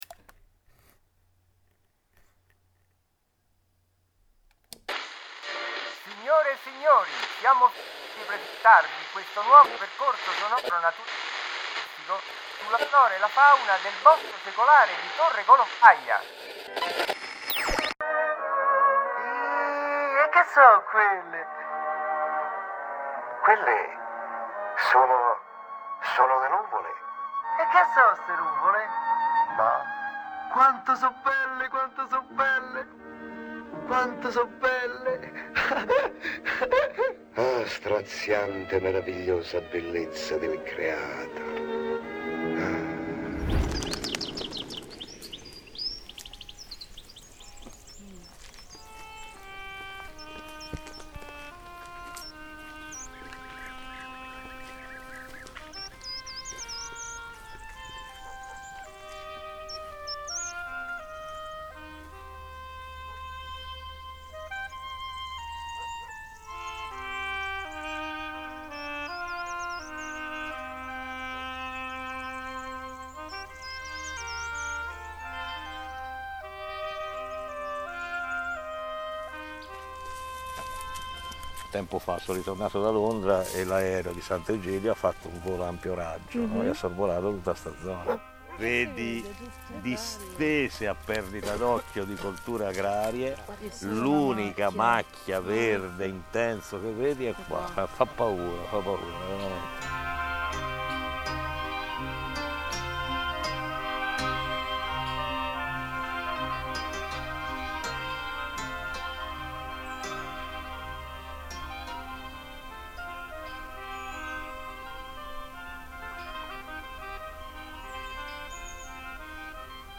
Nel bosco di Torre Colombaia è possibile fare due percorsi sonori, con un lettore mp3 e delle cuffie; ascoltando una voce narrante verrete guidati lungo i sentieri tra gli alberi.
1. Passeggiata sonora sulla flora e la fauna del bosco. 2.
Lungo i sentieri segnalati da targhe numerate si può seguire una particolare visita guidata ascoltando la voce del naturalista accompagnata da musiche e suoni del bosco.
percorso_sonoro_flora_fauna.mp3